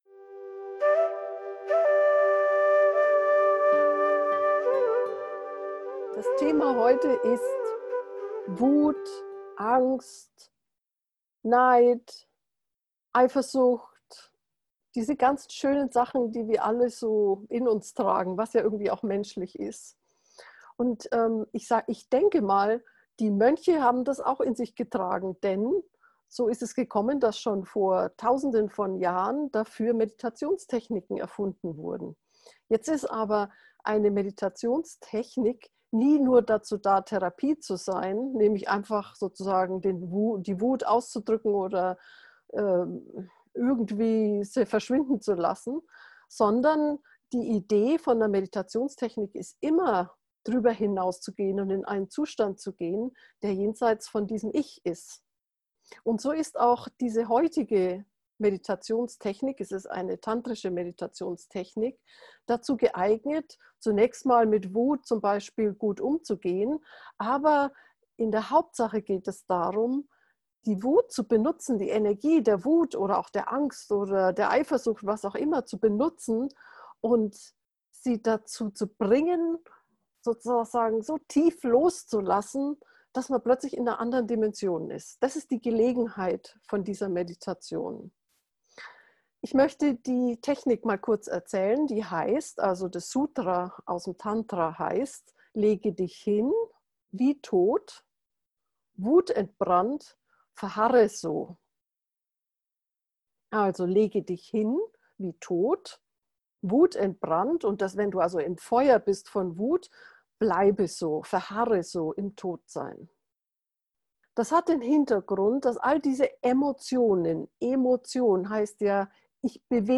Folge 35: Bei Wut lege dich hin – wie tot. Geführte Meditation bei Wut, Angst, Neid, Eifersucht, Selbsthass - FindYourNose